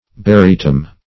Barytum \Ba*ry"tum\, n. [NL.] (Chem.)